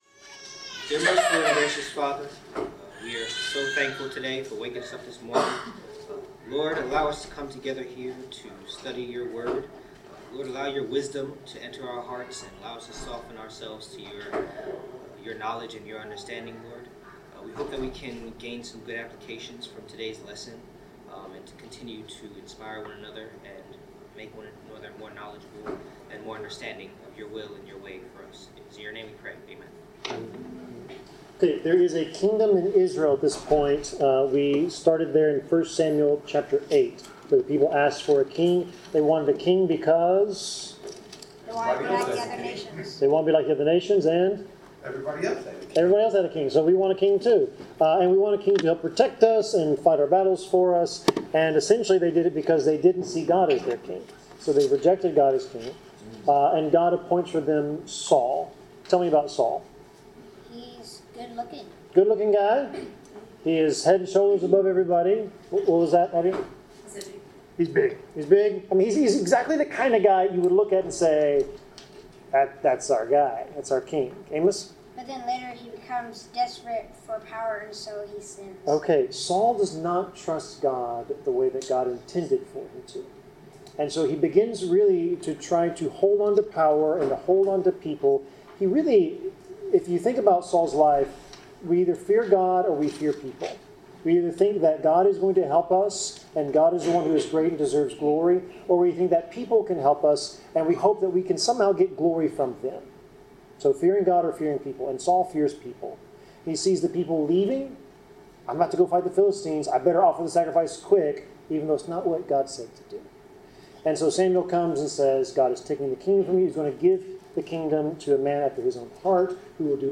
Service Type: Bible Class